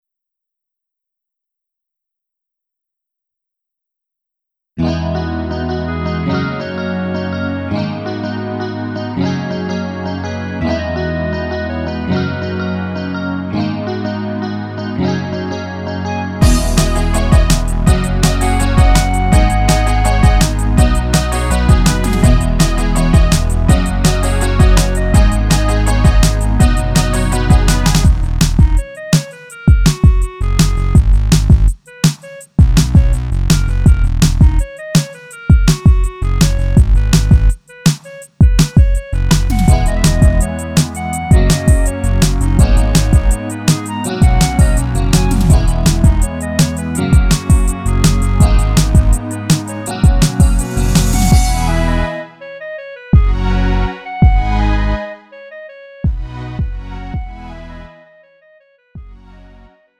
음정 원키 3:14
장르 가요 구분 Lite MR
Lite MR은 저렴한 가격에 간단한 연습이나 취미용으로 활용할 수 있는 가벼운 반주입니다.